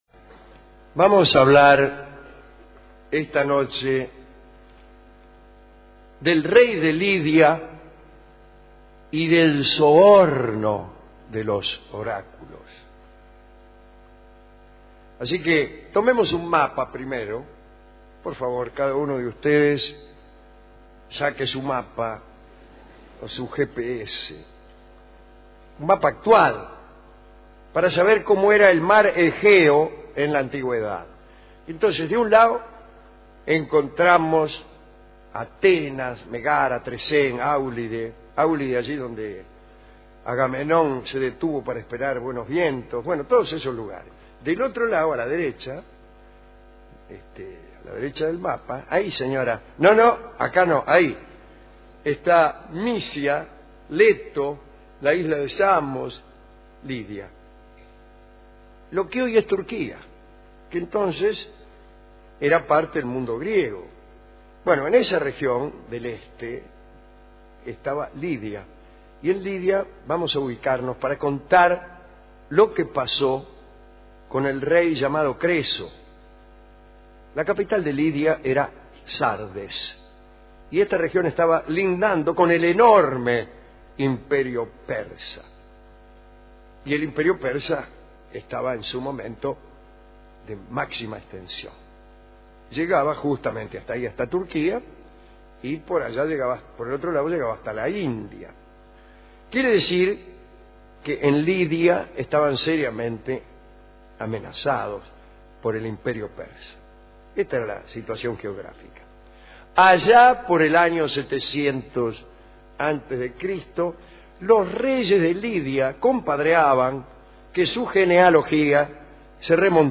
Estudios de Radio El Mundo (AM 1070 kHz), 1987